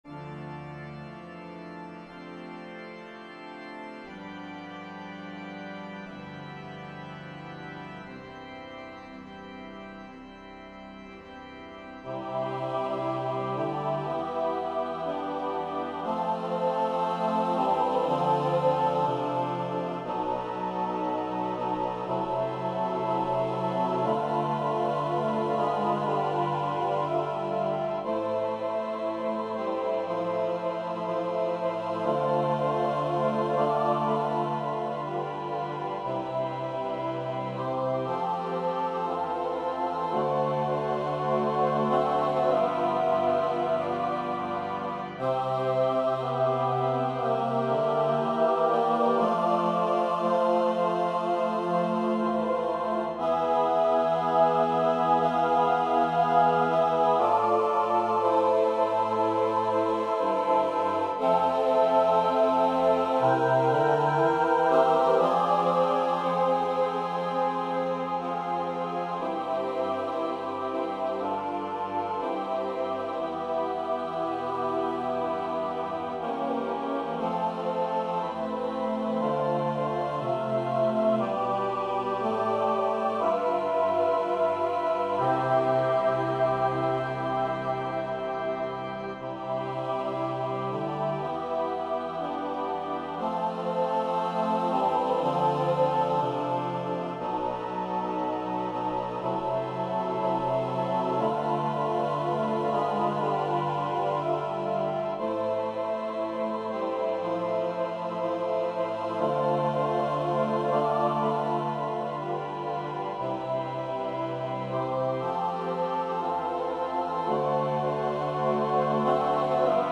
(words not sung)
for SATB Choir and Piano Opus 19